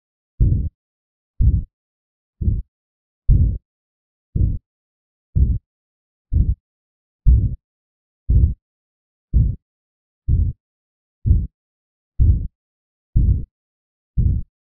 Tricuspid regurgitation
Holosystolic murmur
Left lower sternal border